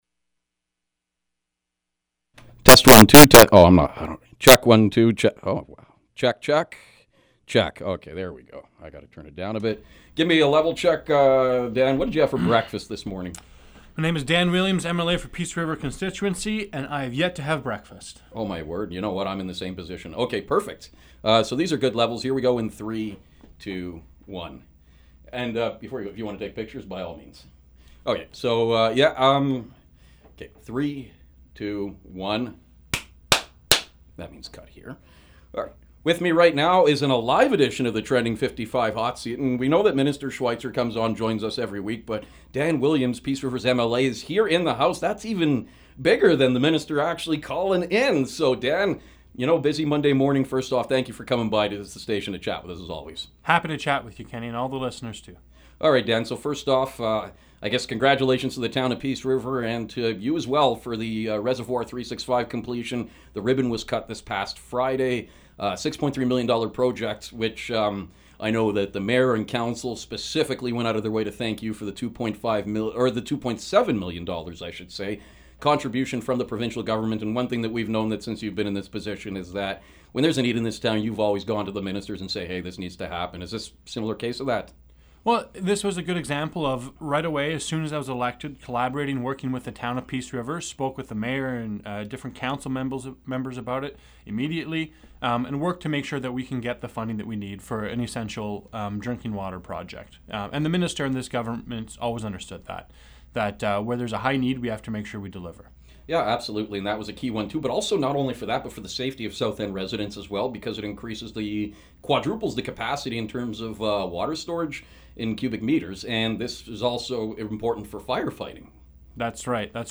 Peace River MLA, Dan Williams dropped by the station for an in-person appearance on the Trending 55 Hot Seat.